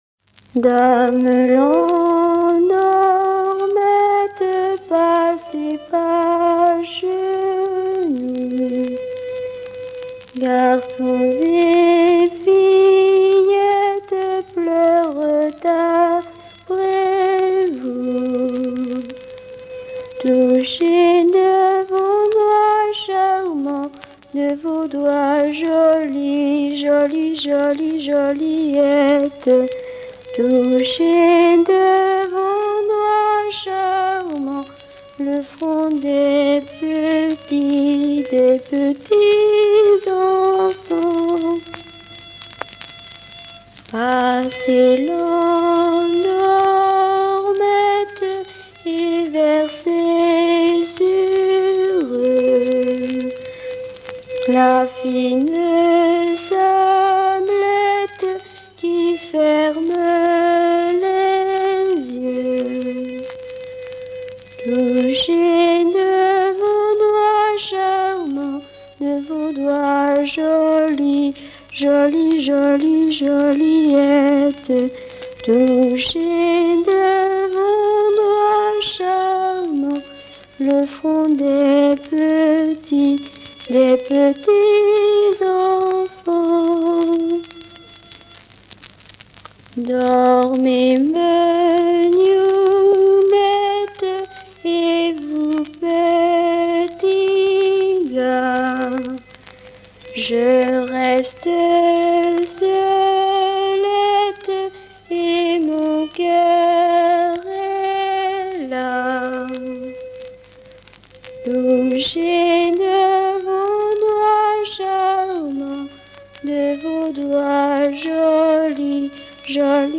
Berceuses diverses
enfantine : berceuse